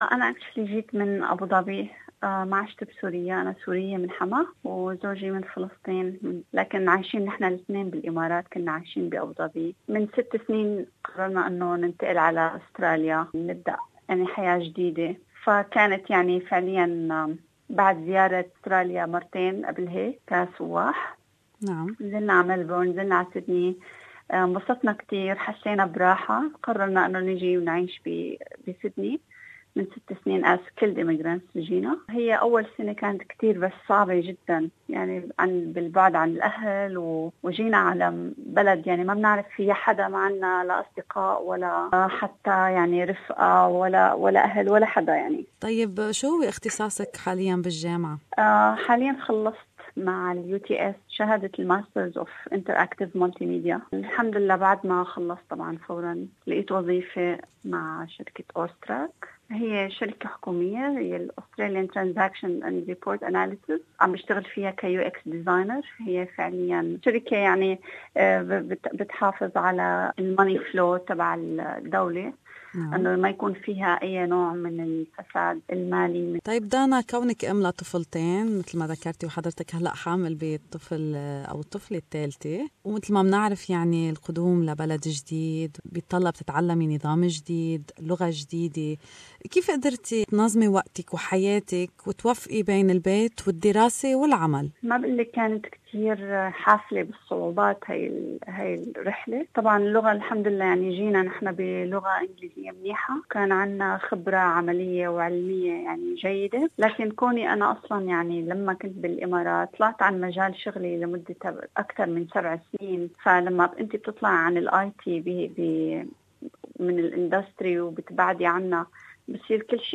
هذه المقابلة